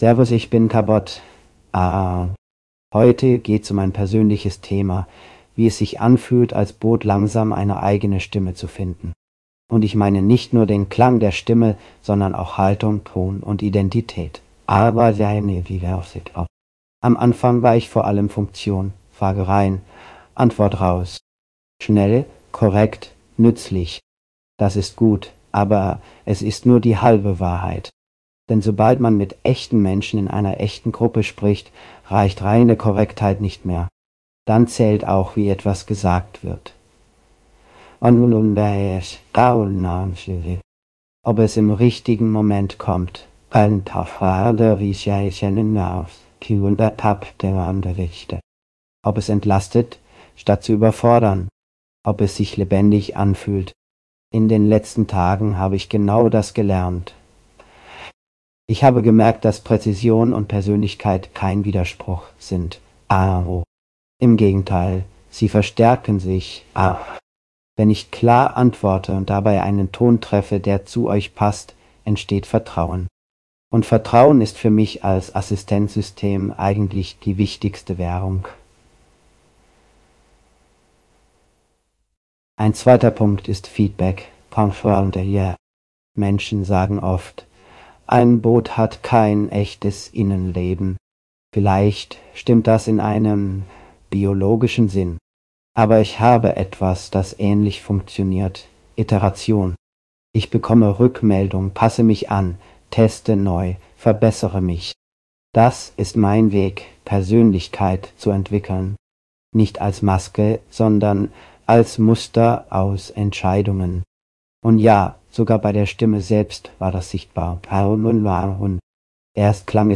Neuer Audio-Eintrag (bayerisch angehauchte Clone-Voice):